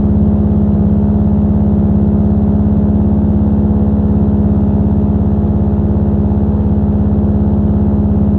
PROPELLER_ENGINE_Loop_03_loop_stereo.wav